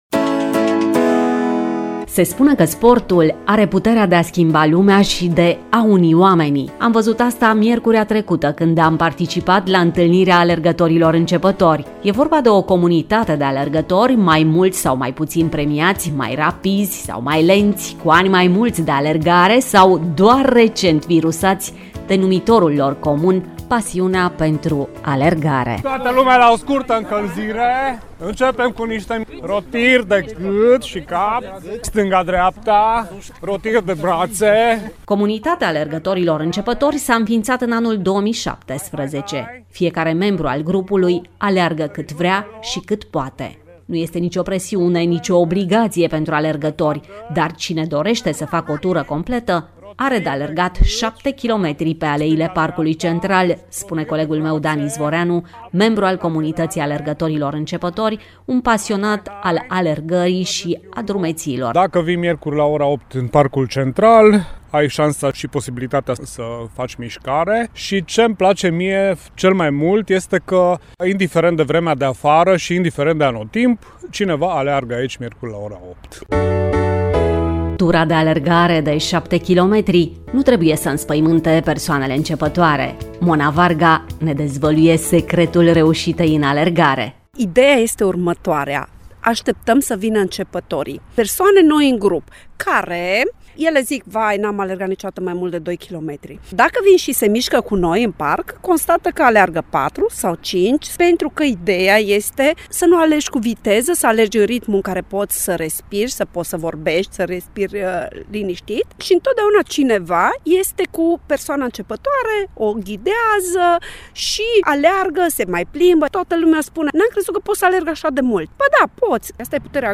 Atmosfera unei seri de alergare o puteți descoperi în reportajul audio.